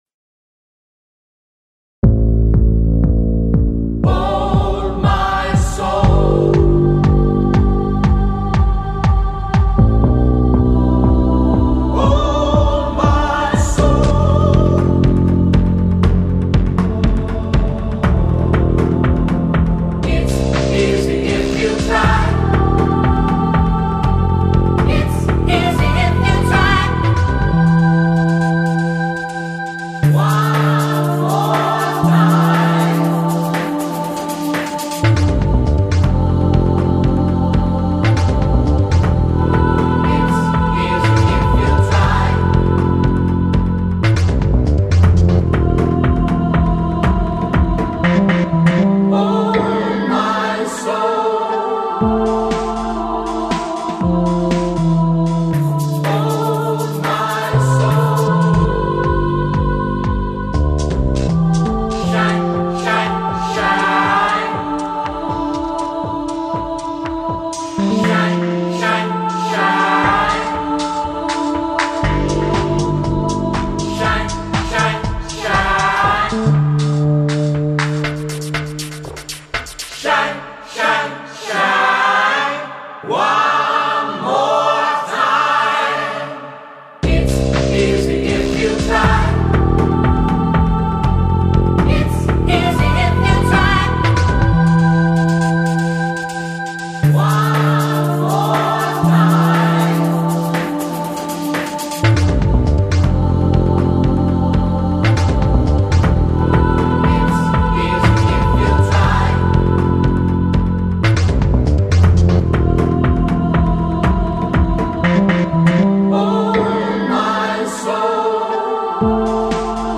SMOOTH JAZZ MUSIC